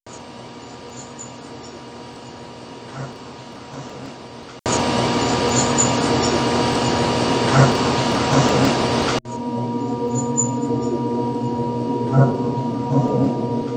Basement
Come Over (or Come Home) - repeated 3X, like above, with the second amplified, and the third with noise or hiss reduciton applied (.wav)